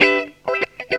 GTR 47 EM.wav